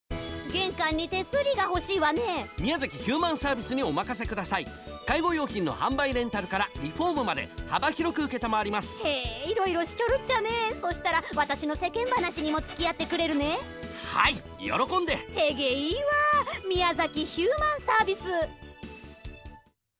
企業CMはコチラ